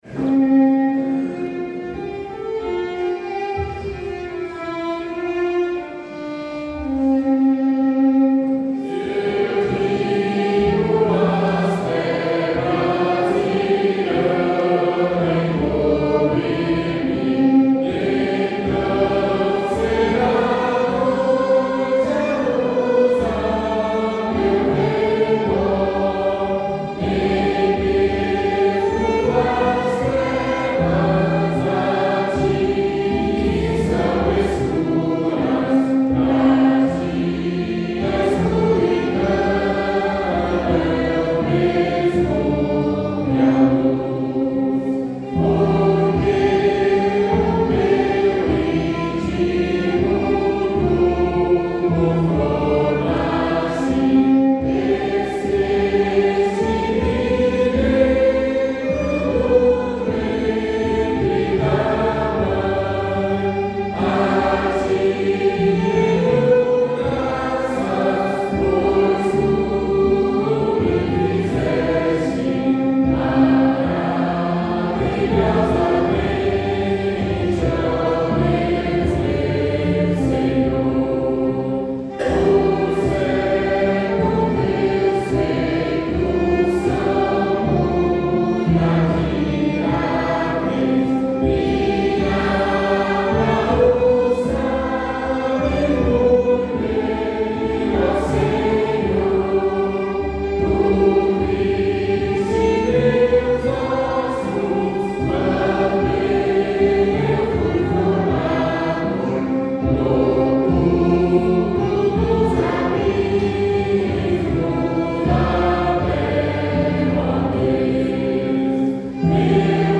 Melodia popular neerlandesa
salmo_139B_cantado.mp3